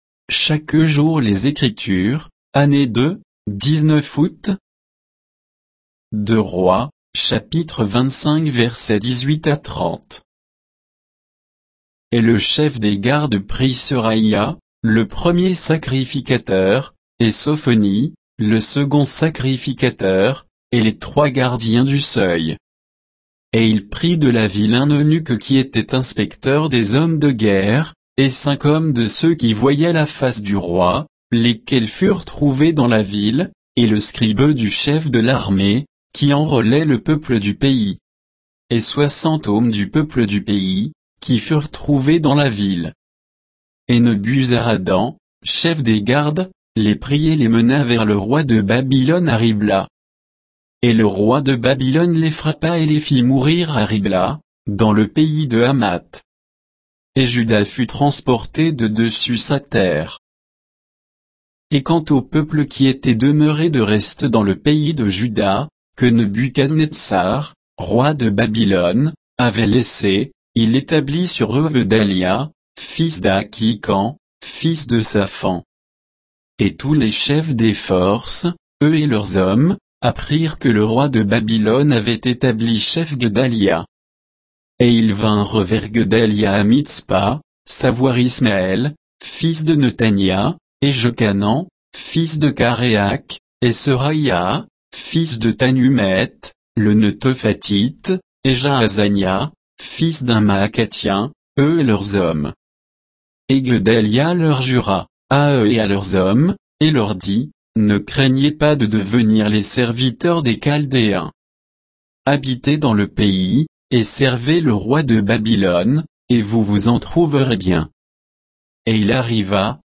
Méditation quoditienne de Chaque jour les Écritures sur 2 Rois 25, 18 à 30